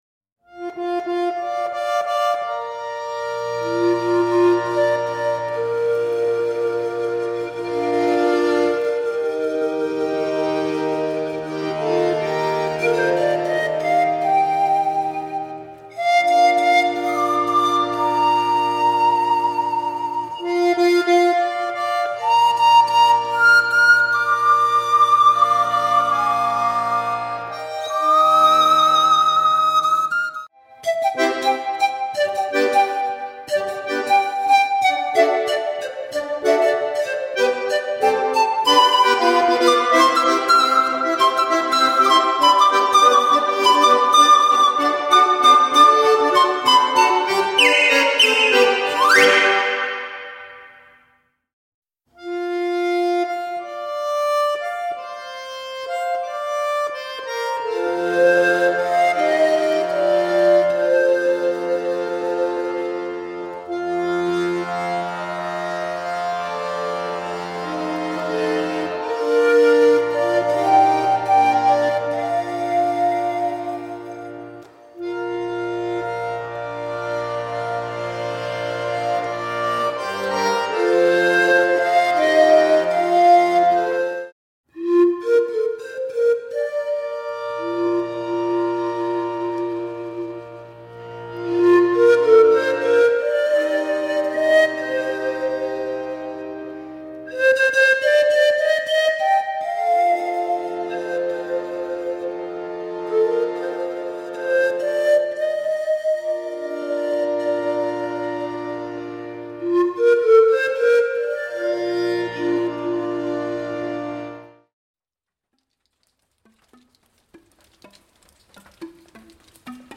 Klänge wie das Singen aus der Vogelkehle